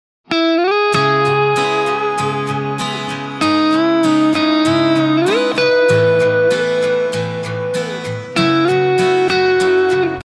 Вырезал щелчки руками из 1-го файла в спектре.